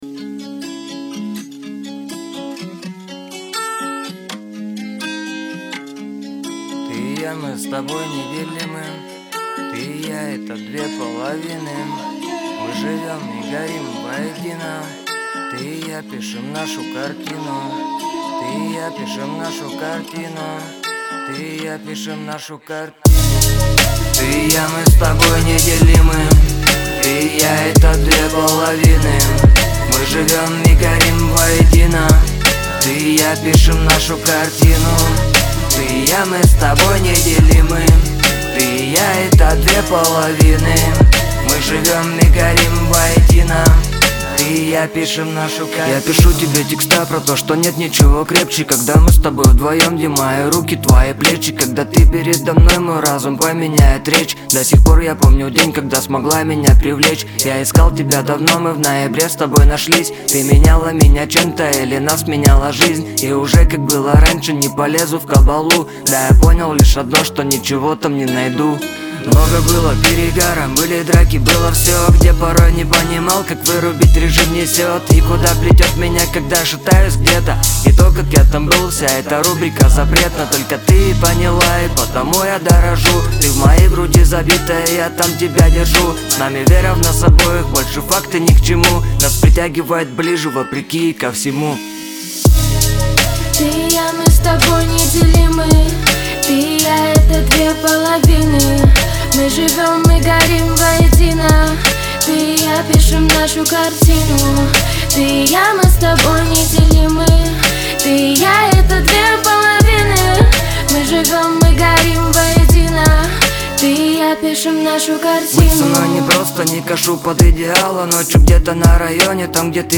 Лирика , дуэт
ХАУС-РЭП